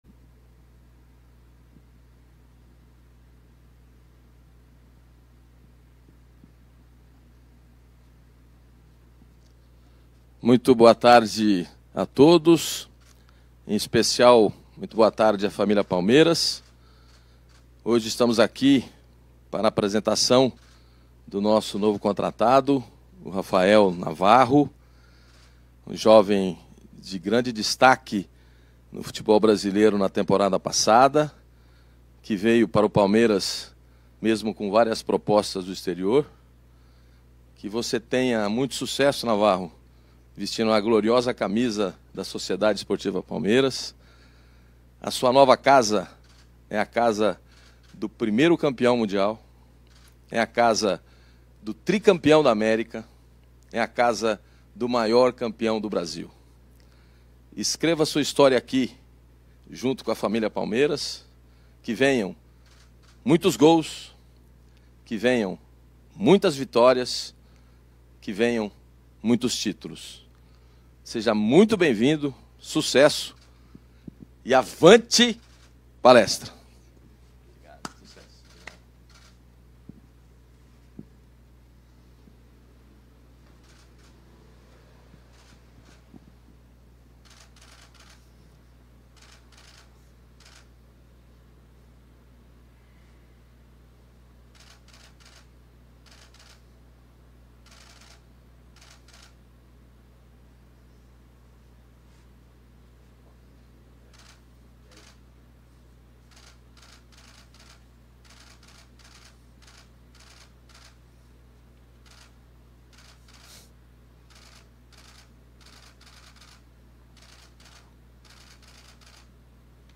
Confira abaixo a apresentação oficial do atacante Rafael Navarro:
APRESENTACAO-_-RAFAEL-NAVARRO.mp3